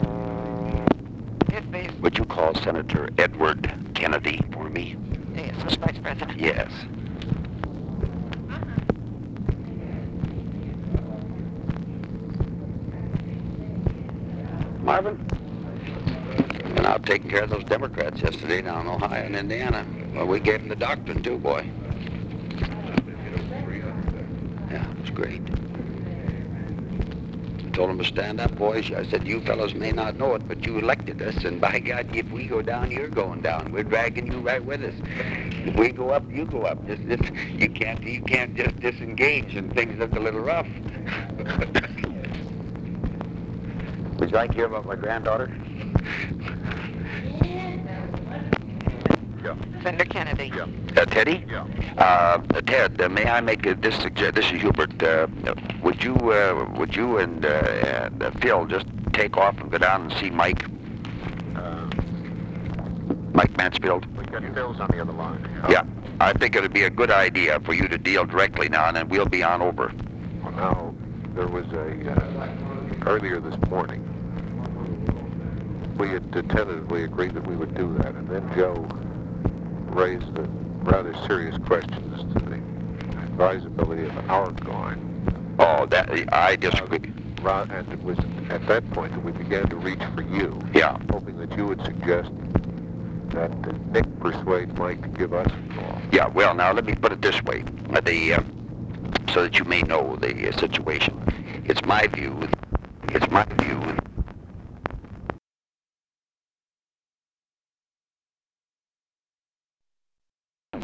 Conversation with EDWARD KENNEDY and OFFICE CONVERSATION
Secret White House Tapes